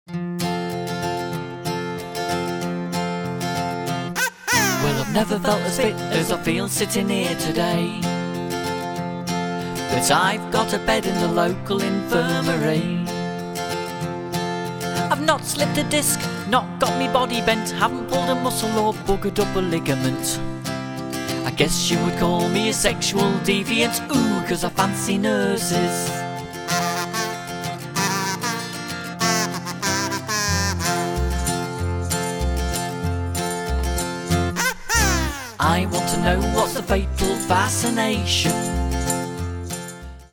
--Comedy Music